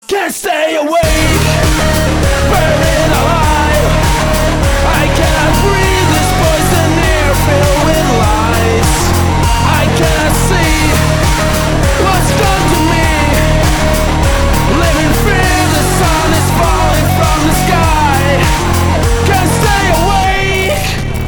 • Качество: 169, Stereo
громкие
EBM
Industrial metal
Aggrotech
жесть